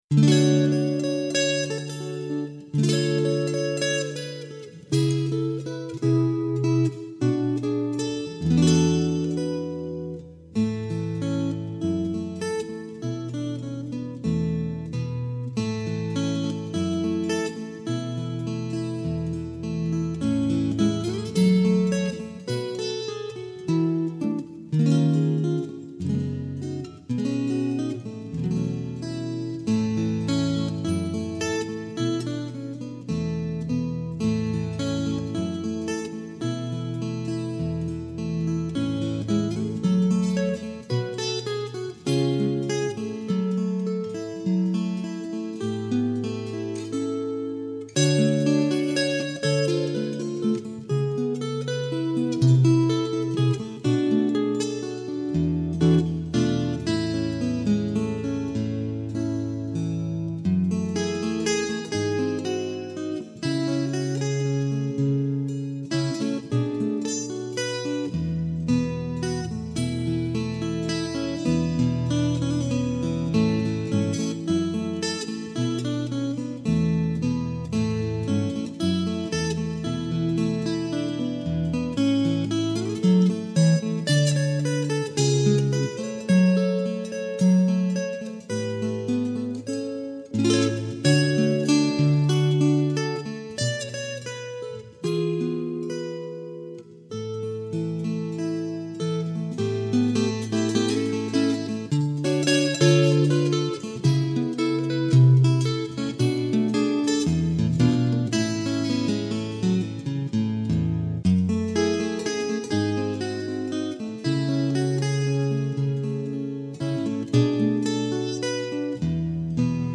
has been digitally remastered
Open G